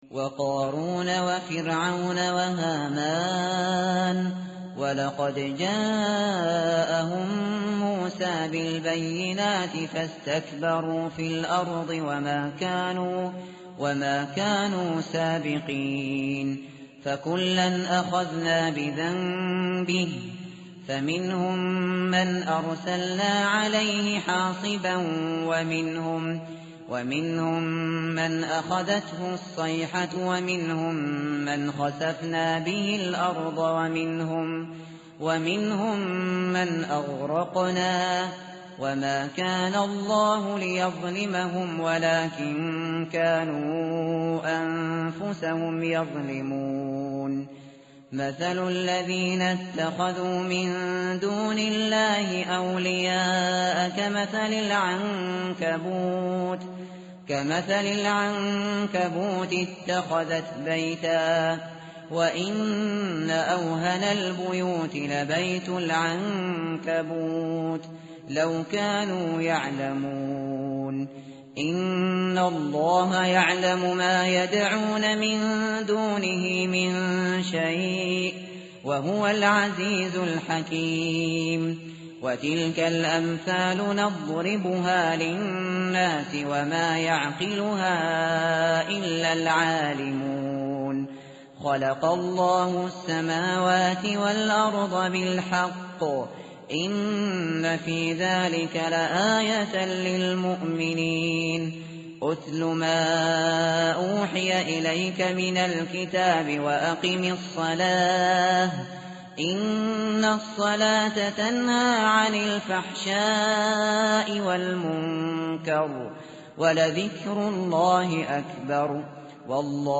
tartil_shateri_page_401.mp3